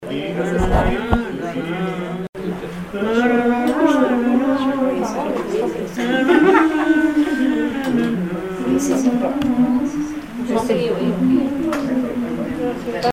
Informateur(s) Club d'anciens de Saint-Pierre association
Genre strophique
Pièce musicale inédite